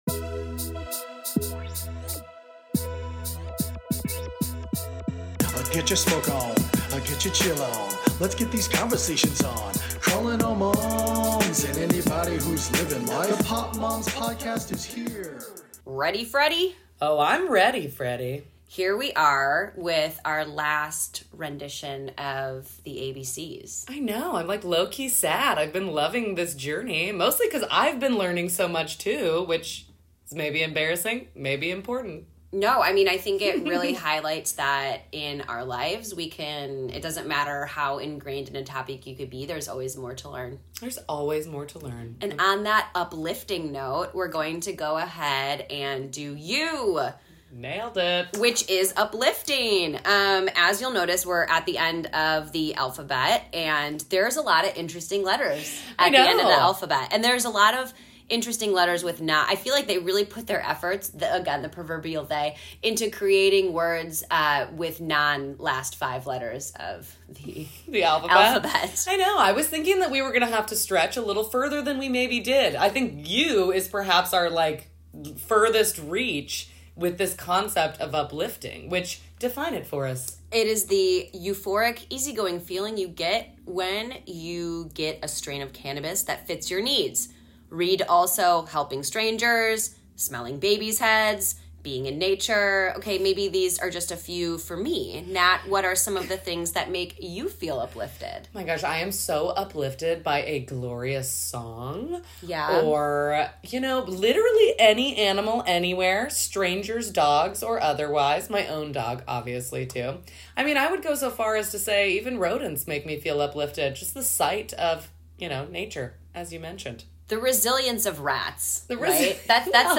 Through our conversations, we hope to provide an open and honest perspective on cannabis